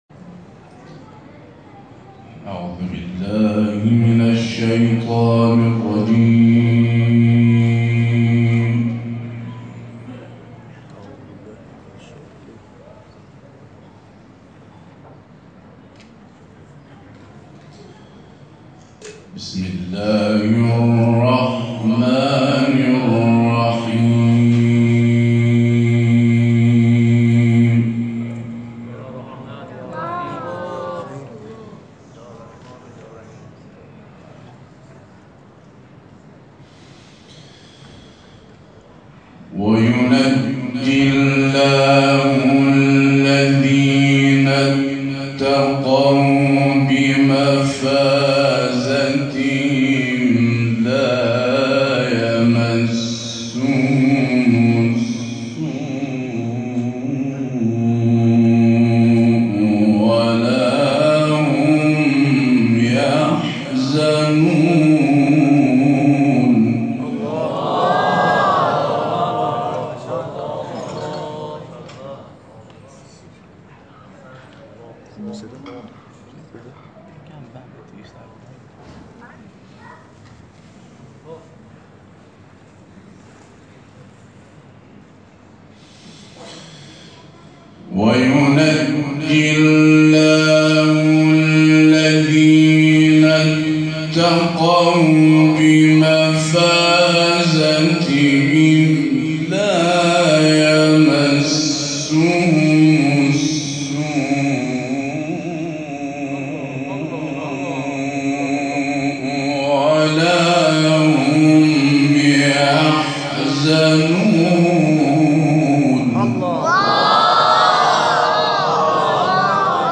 در محفل قرآنی شهر امیرکلا، به تلاوت آیاتی از کلام الله مجید پرداخت.